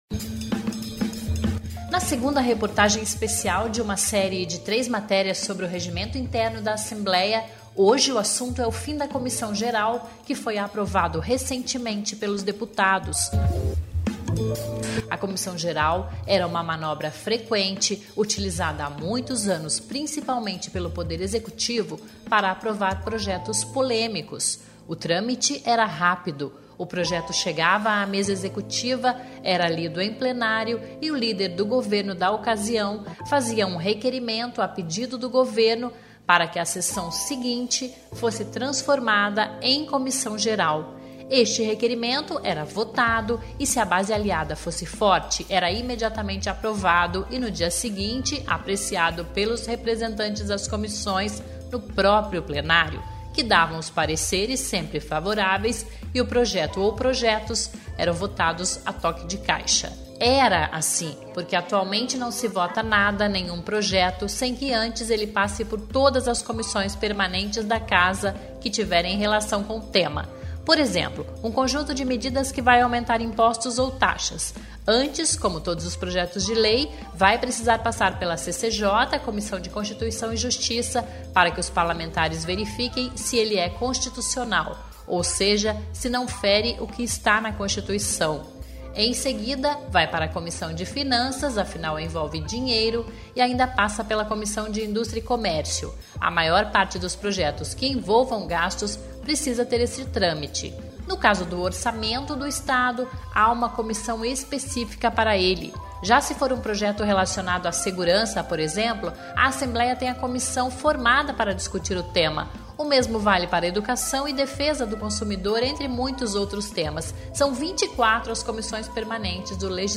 Ouça a segunda reportagem especial sobre Mudanças no Regimento Interno da Assembleia. O assunto é o fim da Comissão geral